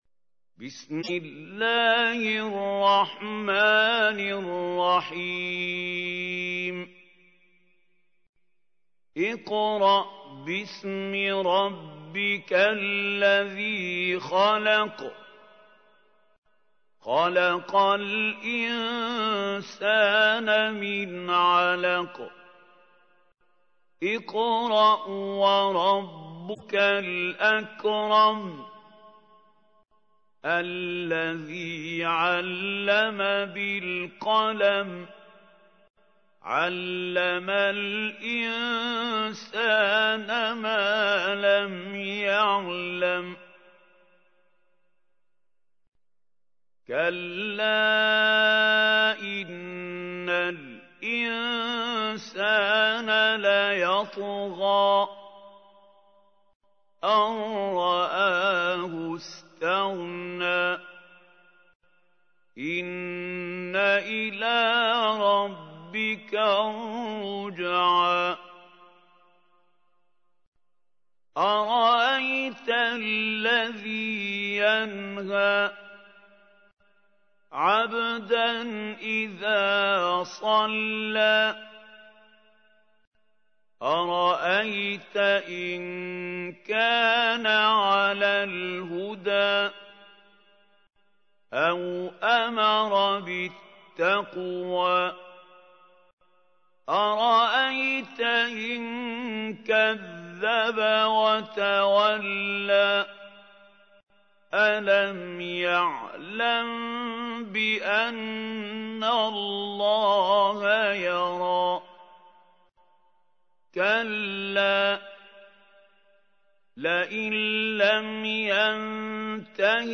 تحميل : 96. سورة العلق / القارئ محمود خليل الحصري / القرآن الكريم / موقع يا حسين